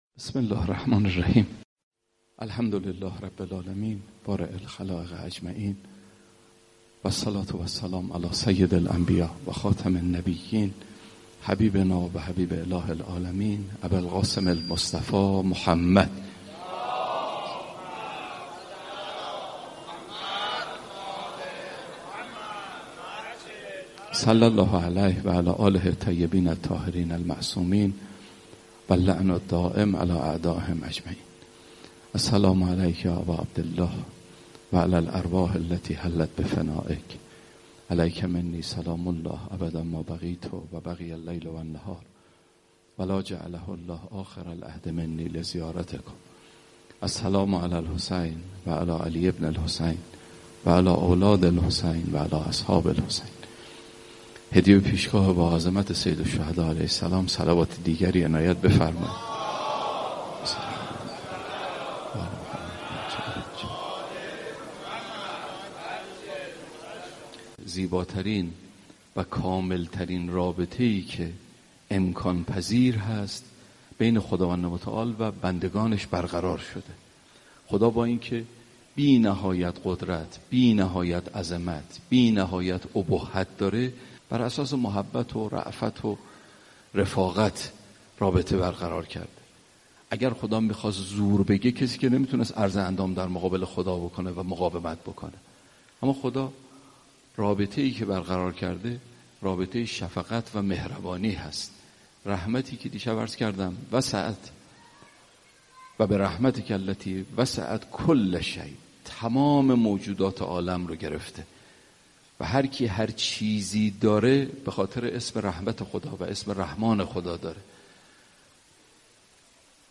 سخنرانی : رابطه بین خداوند و بندگانش رابطه شفقت و مهربانی است و رحمتِ پروردگار تمام موجودات عالم را فرا گرفته است و تصور و فهمِ رحمت الهی برای ما ممکن نیست.